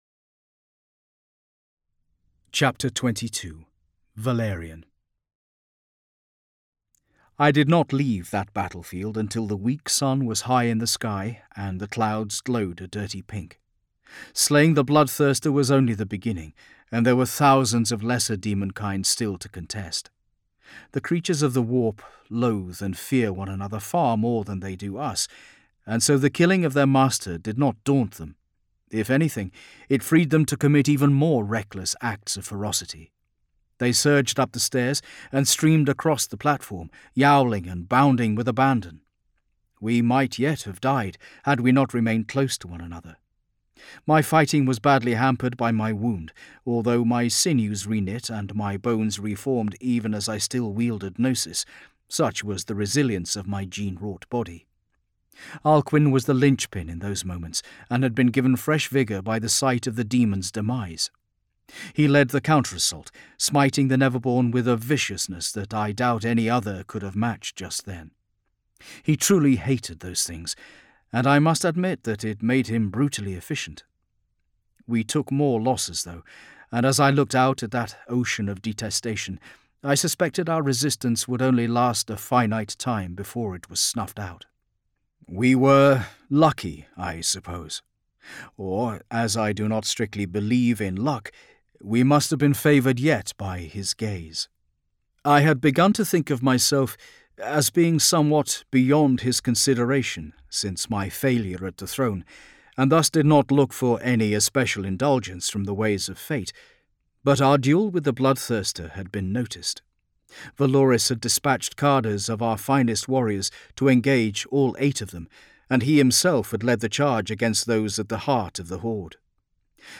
Index of /Games/MothTrove/Black Library/Warhammer 40,000/Audiobooks/Watchers of the Throne/Watchers of the Throne (Book 01) - The Emperor's Legion